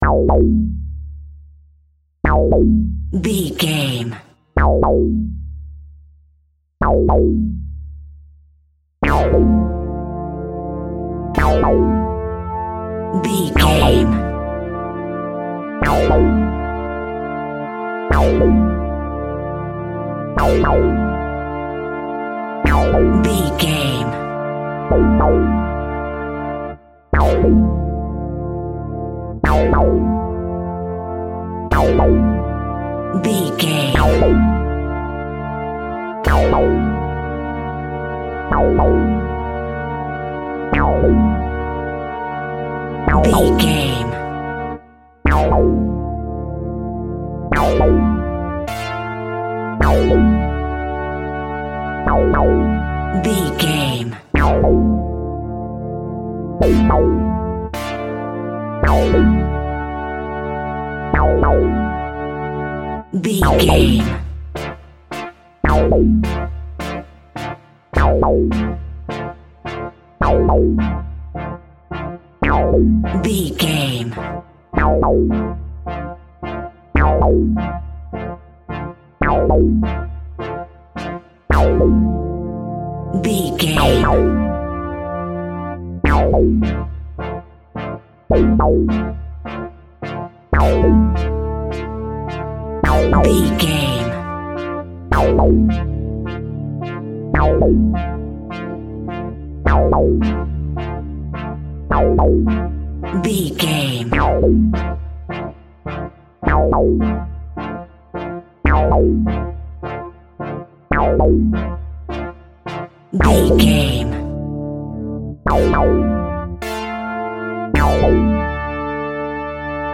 Thriller
Aeolian/Minor
D
scary
tension
ominous
dark
suspense
eerie
epic
brass
Horror synth
Horror Ambience
electronics
synthesizer